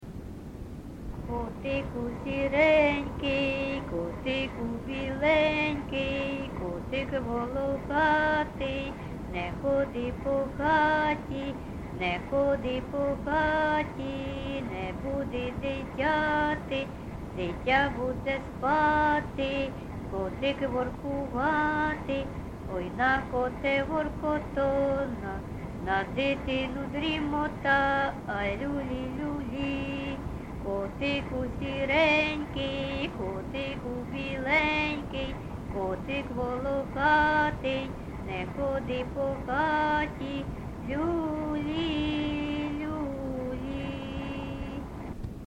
ЖанрКолискові
Місце записус. Харківці, Миргородський (Лохвицький) район, Полтавська обл., Україна, Полтавщина